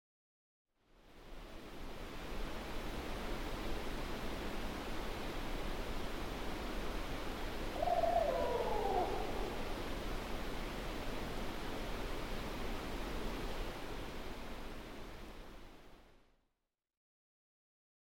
Barred Owl, Clatsop County Oregon.